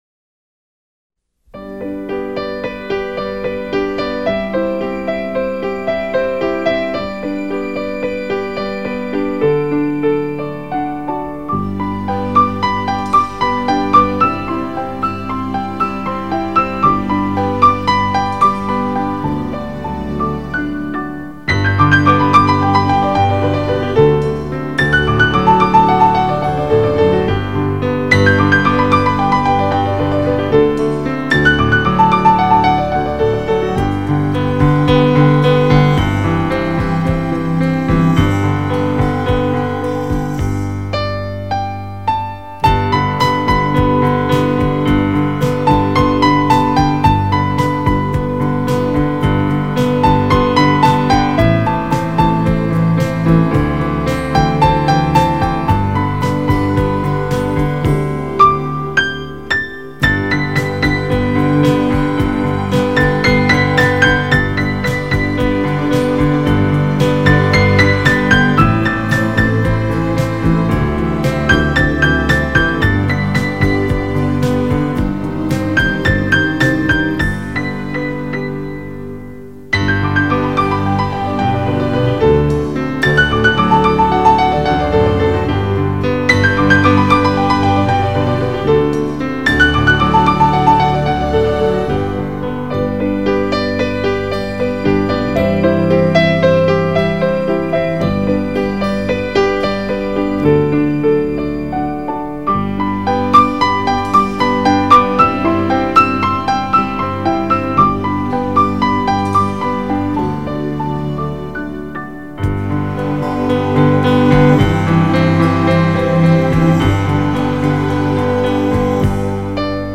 경음악